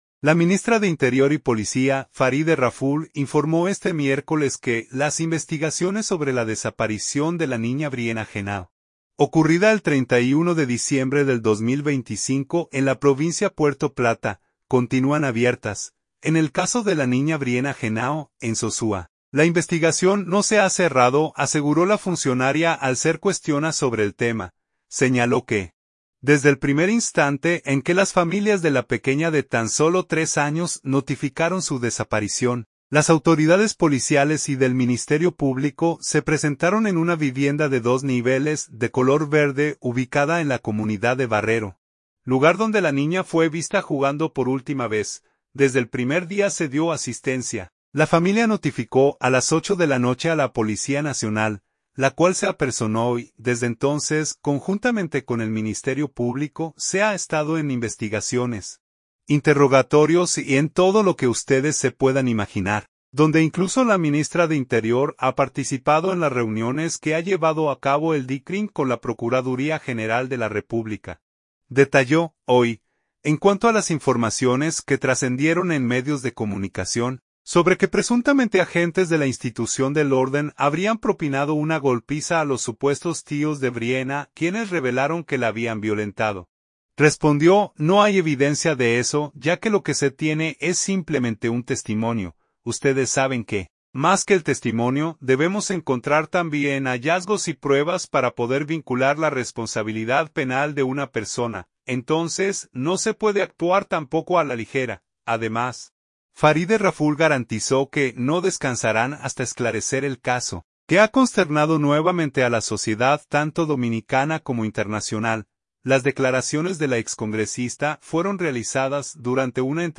Las declaraciones de la excongresista fueron realizadas durante una entrevista en el programa de televisión Hoy Mismo Matinal.